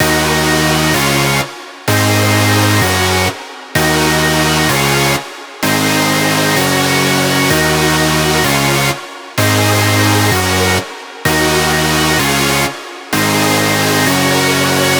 VDE 128BPM Close Synths 1 Root D#.wav